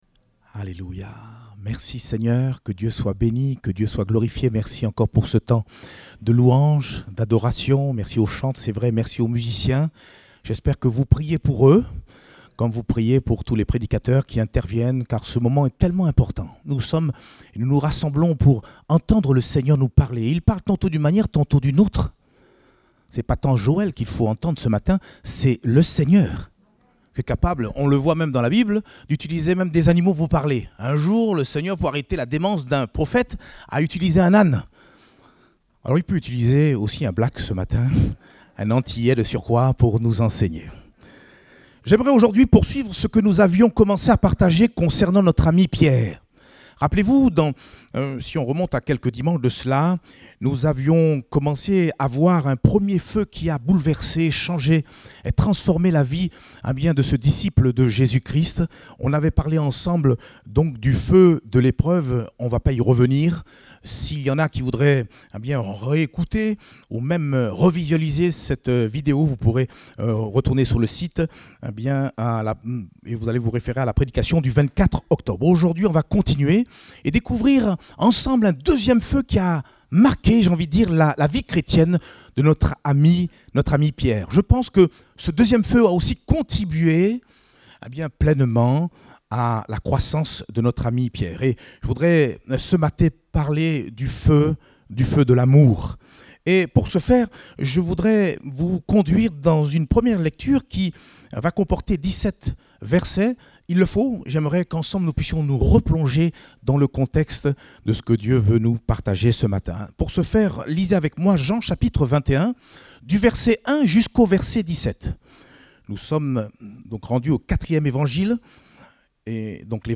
Date : 7 novembre 2021 (Culte Dominical)